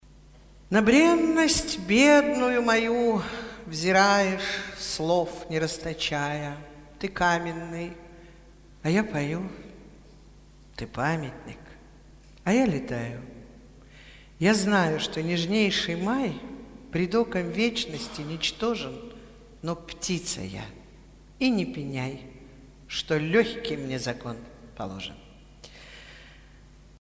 Cvetaeva-Na-brennost-bednuyu-moyu.-chitaet-Svetlana-Kryuchkova-stih-club-ru.mp3